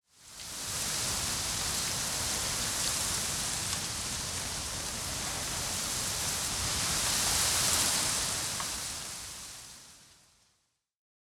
windtree_4.ogg